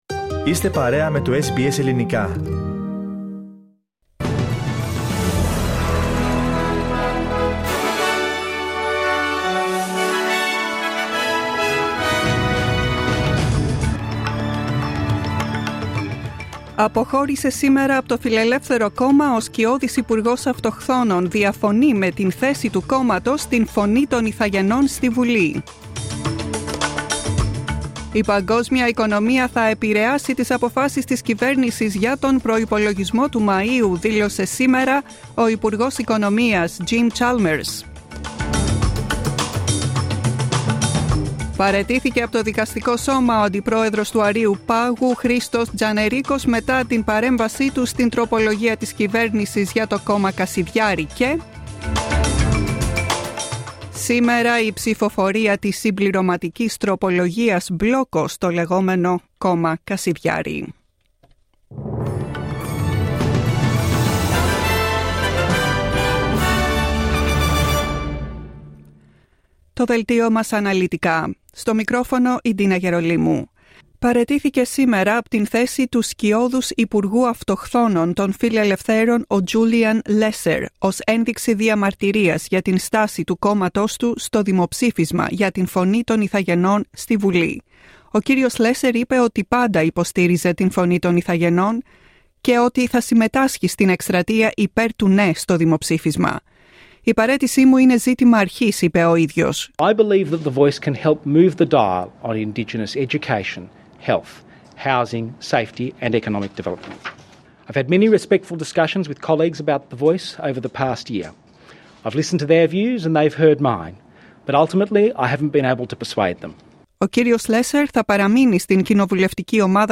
News bulletin, 11 April 2023